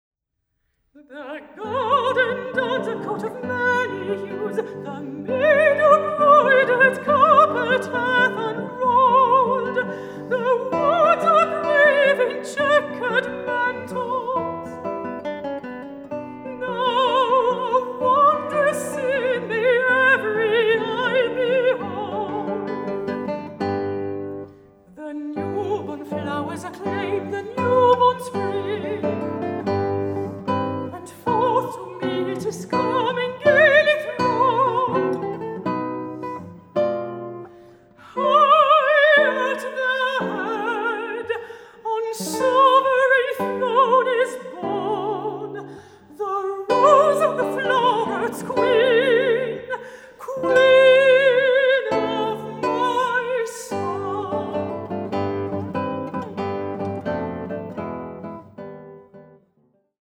guitar
mezzo-soprano